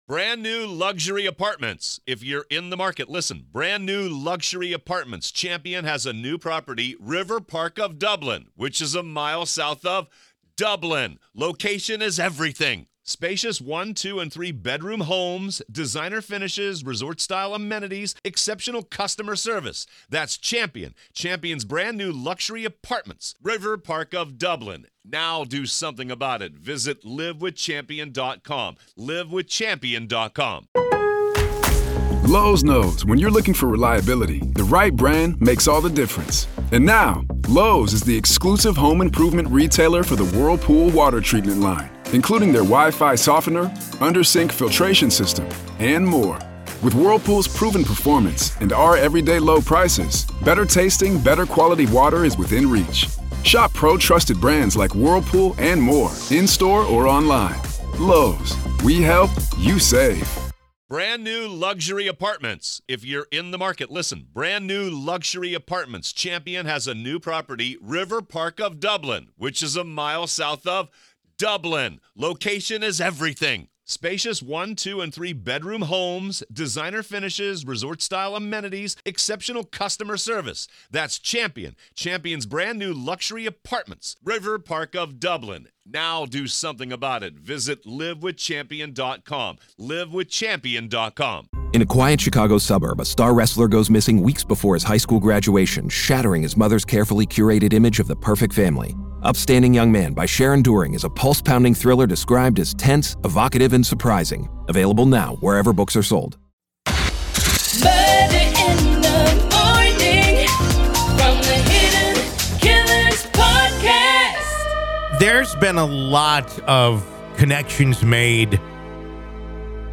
True Crime Today | Daily True Crime News & Interviews / How Similar Is Bryan Kohberger to Ted Bundy?